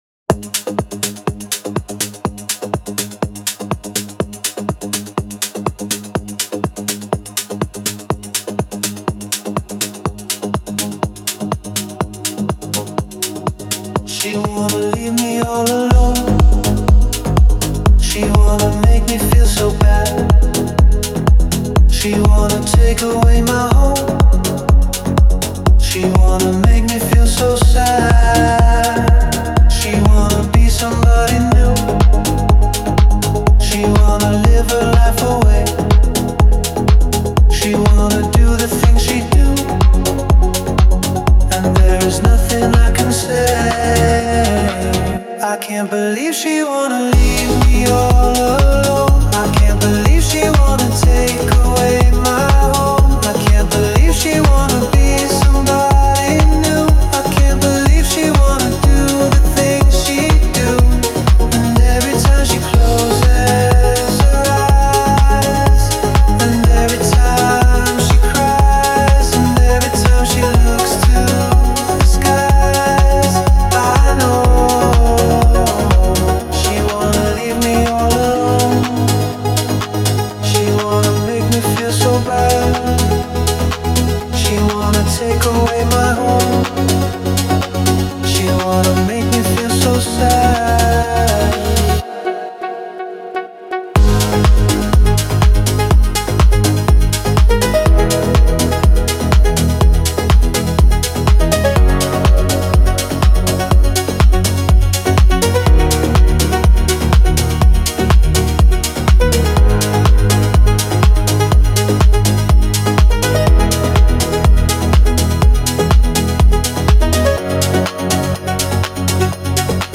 Жанр: Melodic House & Techno